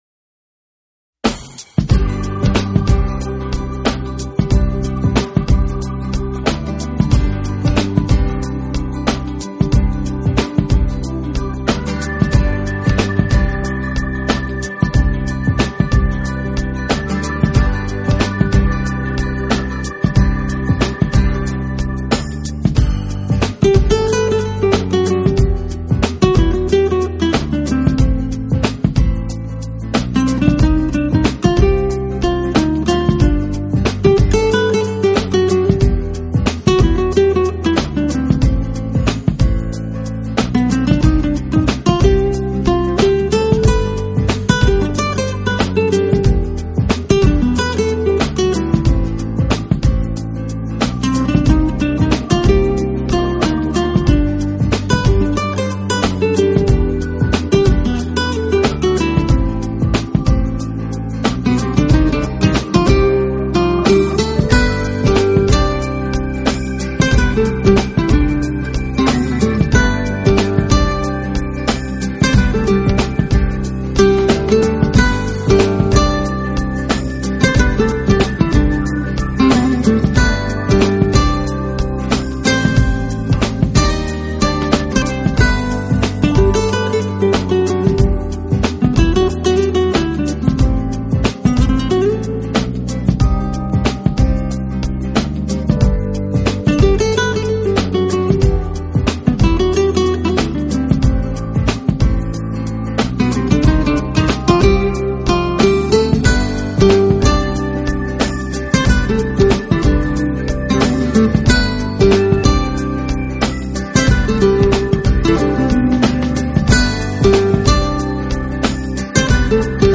风格的，西班牙、拉丁音乐元素随处可闻，而同时爵士音乐元素的表现也是相当不稳定的，
独树一帜的拉丁Smooth Jazz风格证明了自己的实力。